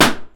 Balloon-Burst-06
balloon burst pop sound effect free sound royalty free Sound Effects